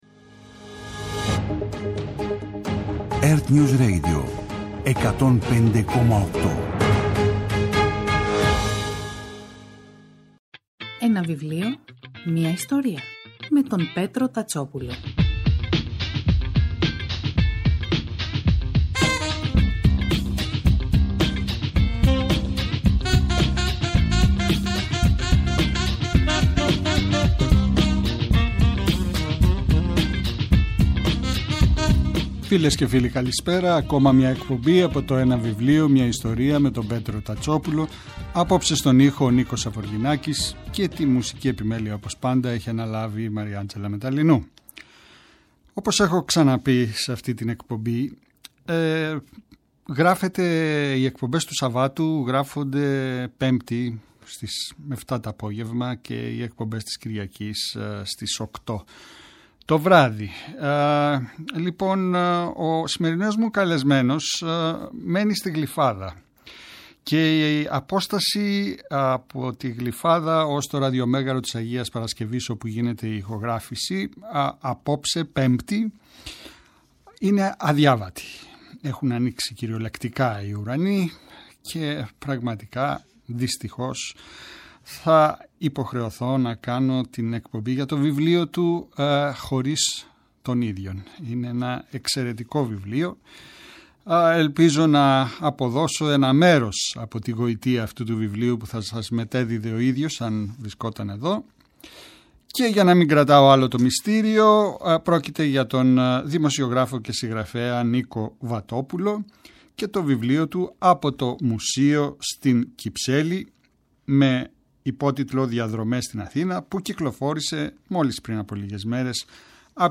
Κάθε Σάββατο και Κυριακή, στις 5 το απόγευμα στο ertnews radio της Ελληνικής Ραδιοφωνίας ο Πέτρος Τατσόπουλος, παρουσιάζει ένα συγγραφικό έργο, με έμφαση στην τρέχουσα εκδοτική παραγωγή, αλλά και παλαιότερες εκδόσεις.